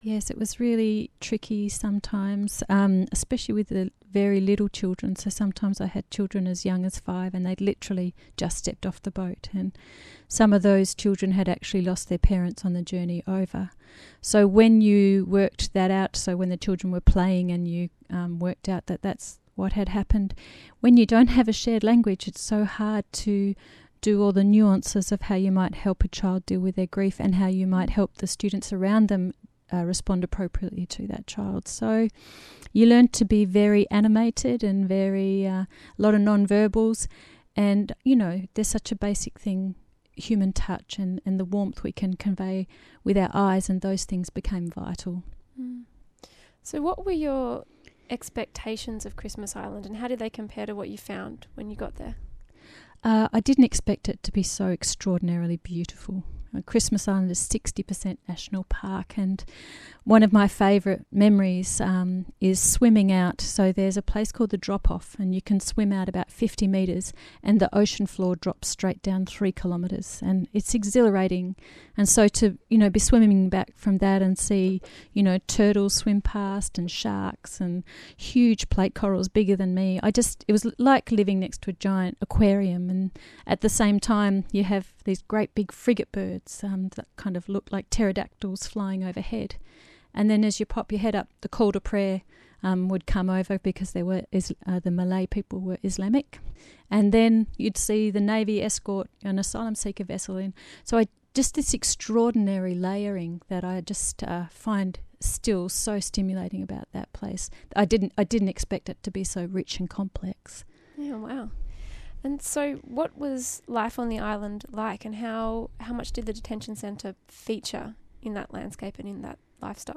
Interview with ABC Great Southern